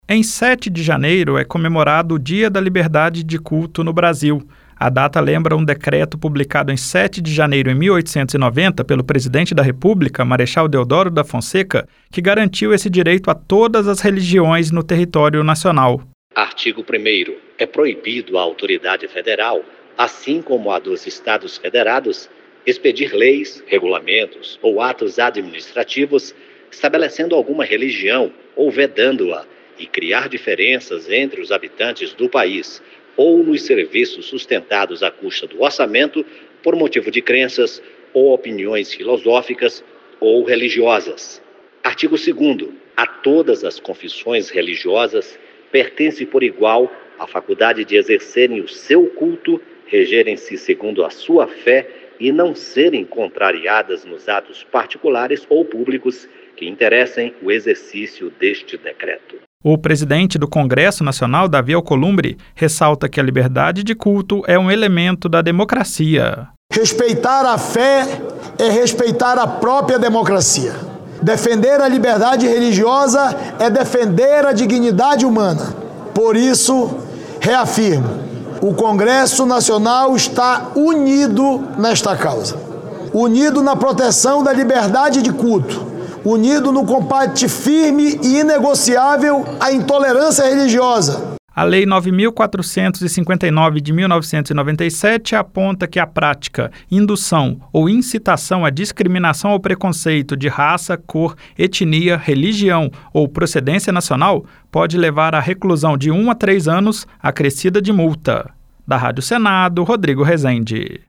Senador Davi Alcolumbre